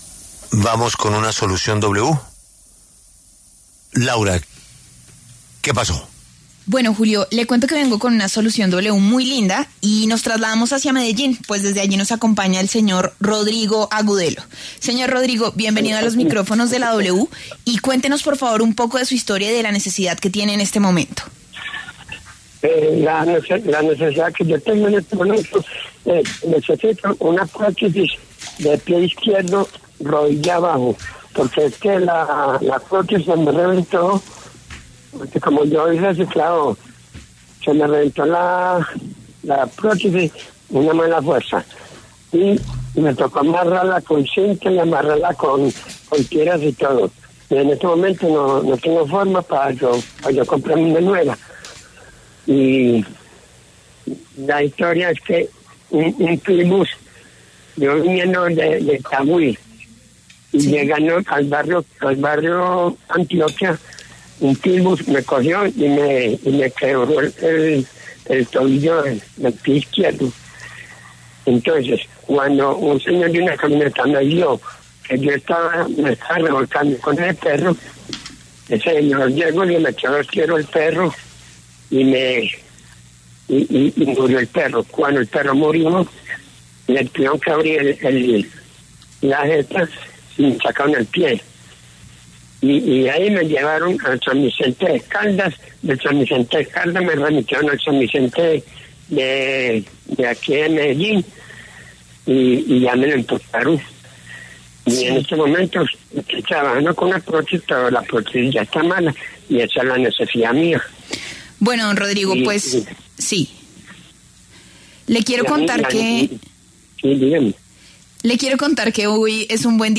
Su historia llegó a los micrófonos de La W, donde se unieron fuerzas junto a una organización que ha cambiado miles de vidas: la Fundación CIREC.